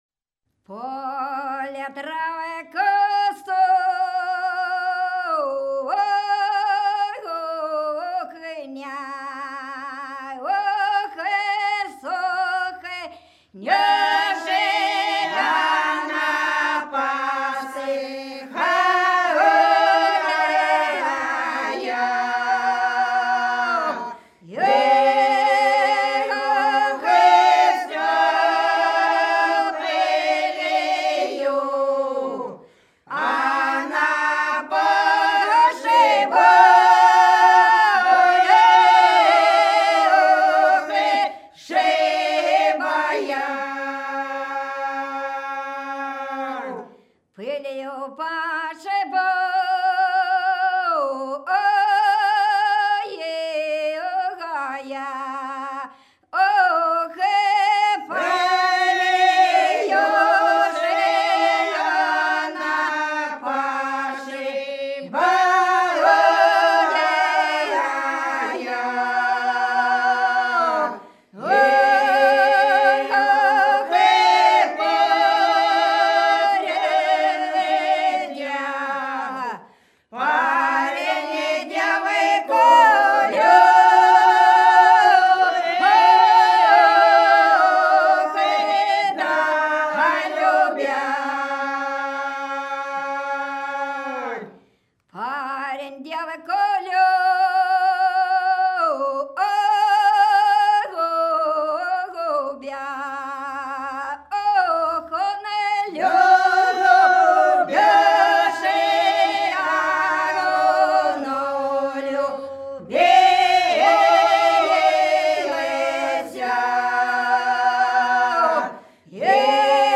Хороша наша деревня В поле травка сохнет - протяжная (с. Глуховка)
19_В_поле_травка_сохнет_-_протяжная.mp3